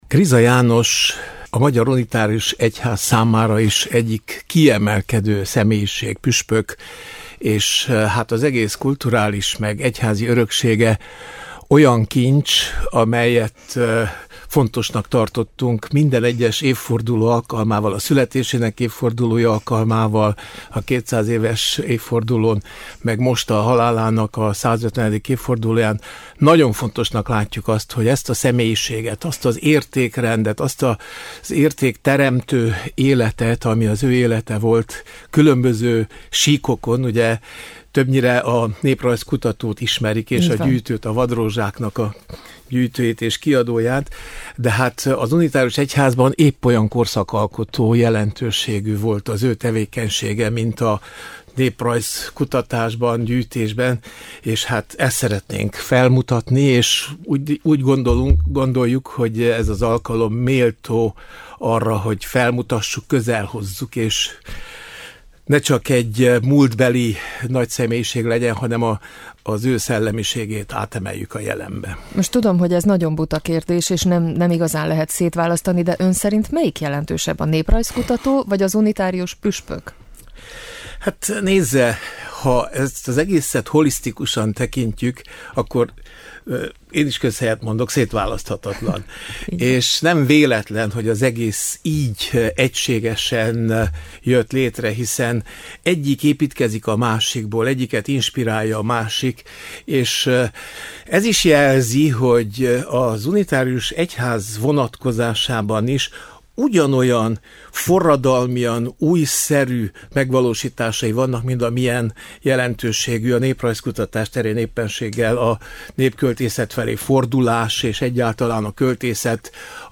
A Hangolóban vendégünk volt a magyar unitárius egyház püspöke, Kovács István, aki azt is elmondta, miért volt fontos számukra Kriza-emlékévvé nyilvánítani 2025-öt.